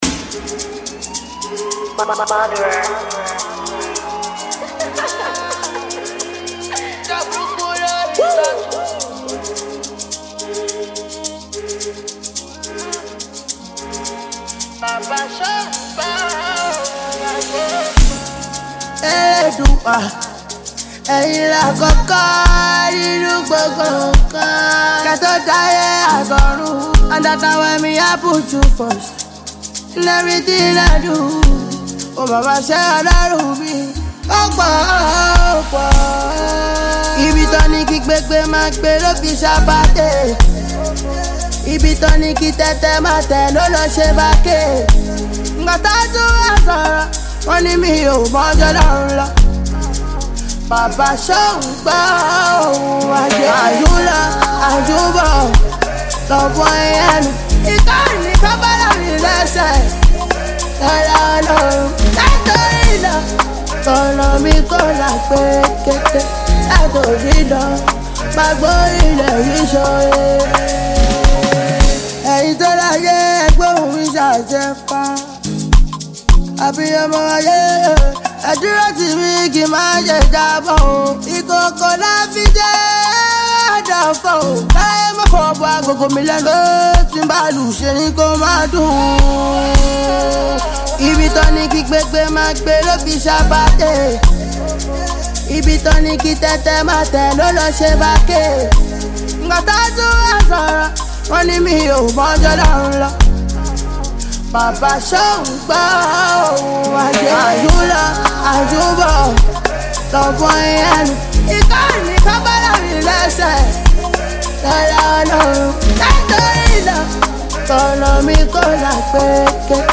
latest naija song